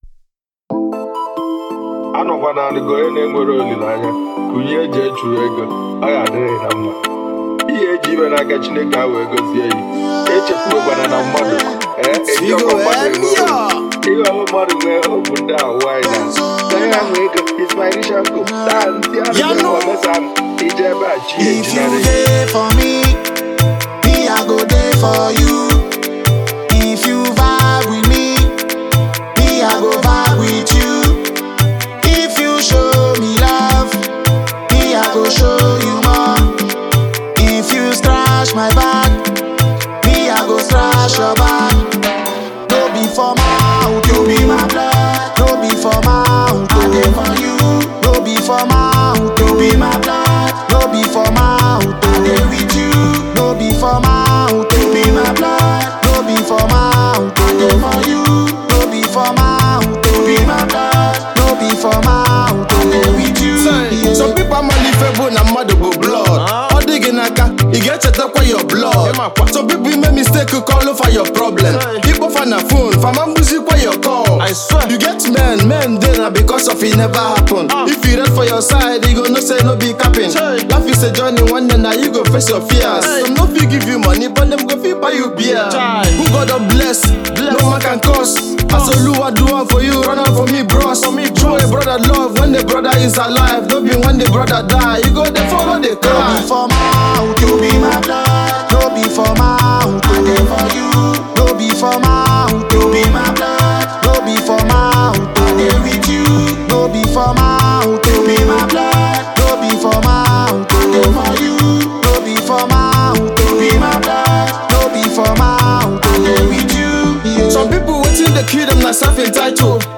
raw voice